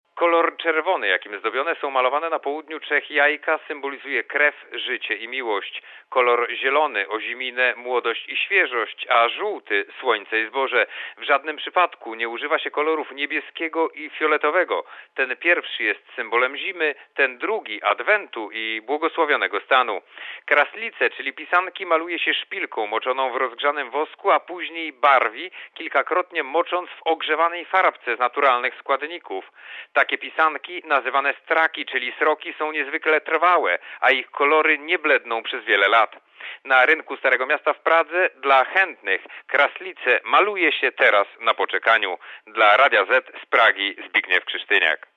RadioZet) Korespondencja Z Czech (350Kb) Oceń jakość naszego artykułu: Twoja opinia pozwala nam tworzyć lepsze treści.